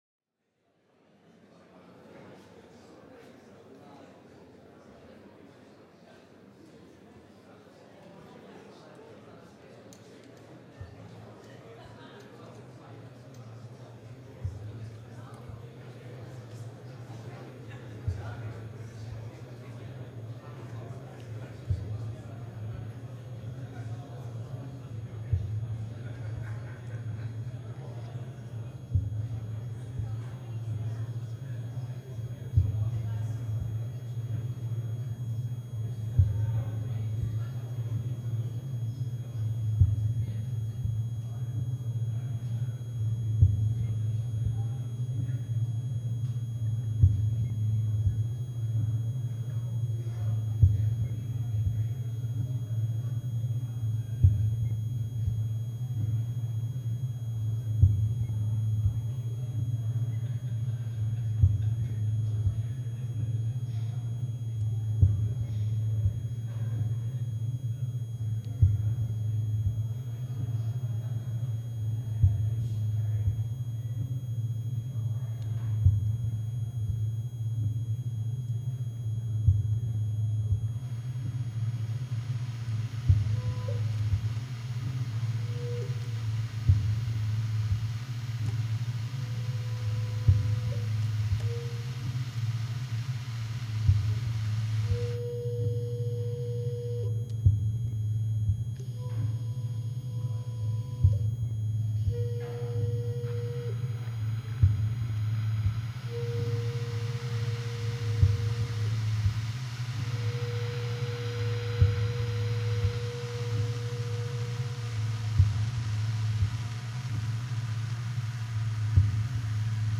soprano saxophonist
trumpeter